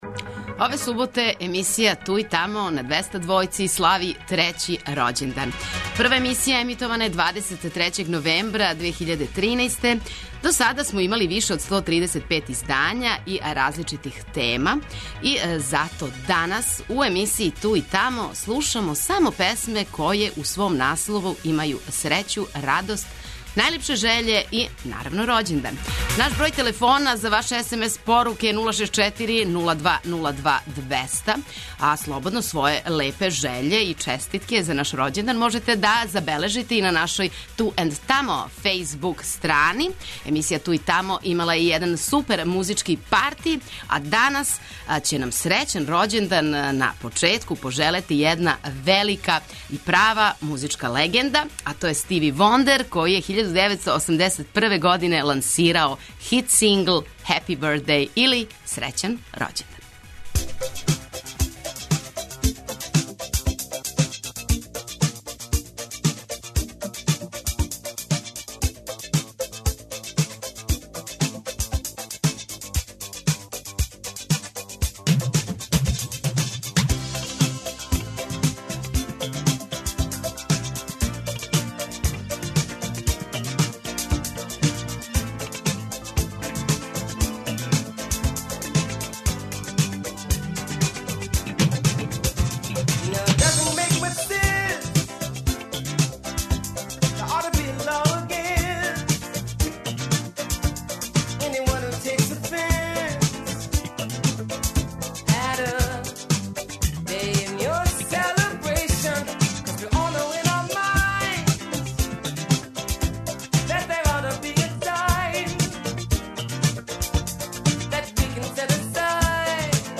Ове суботе музички серијал 'Ту и тамо' на Двестадвојци слави трећи рођендан!
Очекују вас велики хитови, страни и домаћи, стари и нови, супер сарадње, песме из филмова, дуети и још много тога.